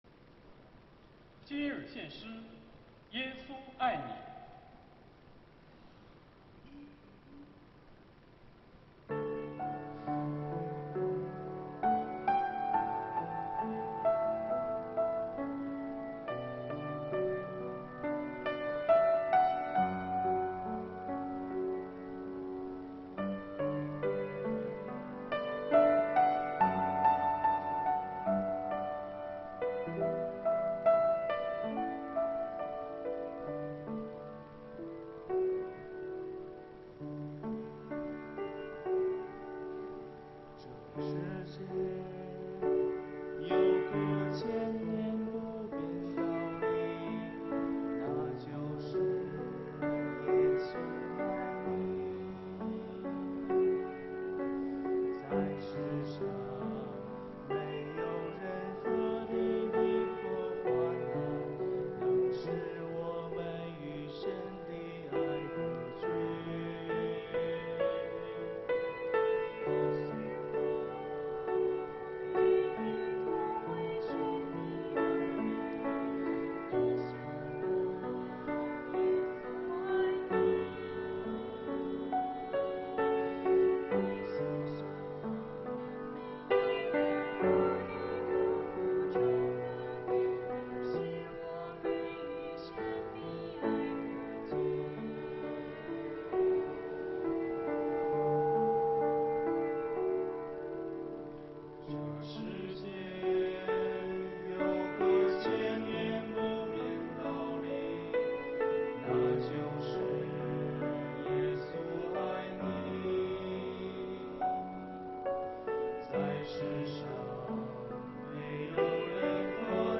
团契名称: 青年诗班 新闻分类: 诗班献诗 音频: 下载证道音频 (如果无法下载请右键点击链接选择"另存为") 视频: 下载此视频 (如果无法下载请右键点击链接选择"另存为")